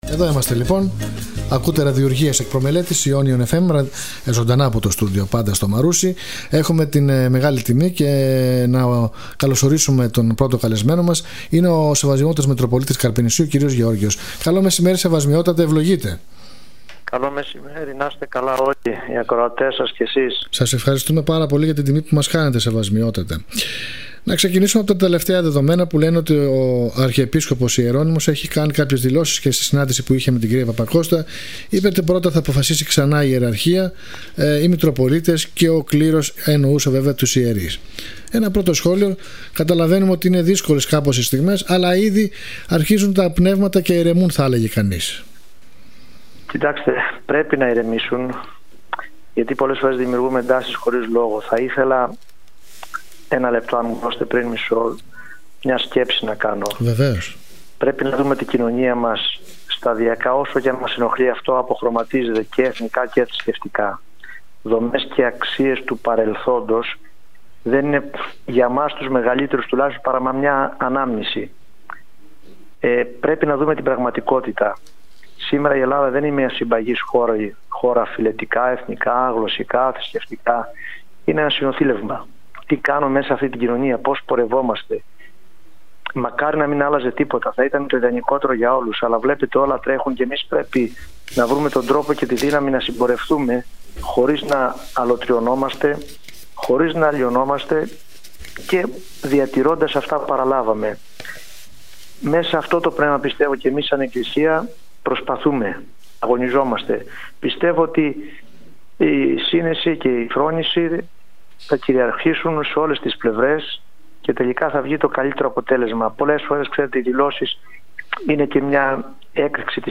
Ο Μητροπολίτης Καρπενησίου στον Ionion fm
Ο Μητροπολίτης Καρπενησίου κ. Γεώργιος μίλησε στον δημοσιογράφο